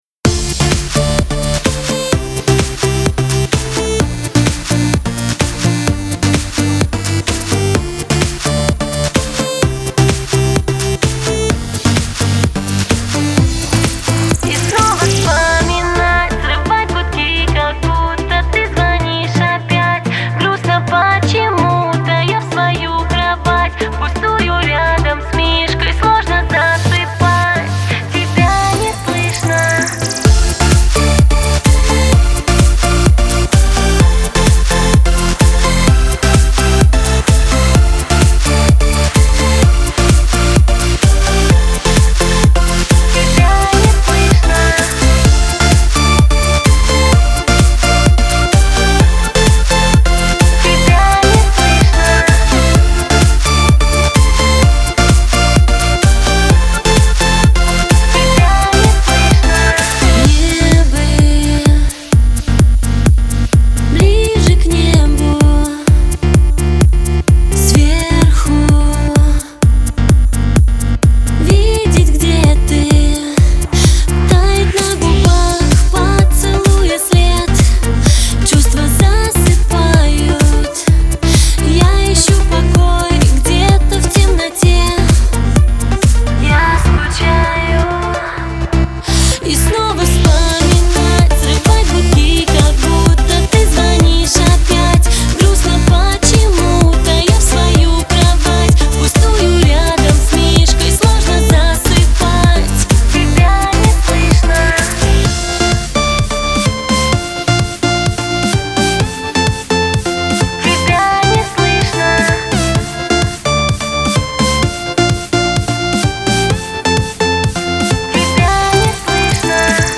лирических, трогательных песен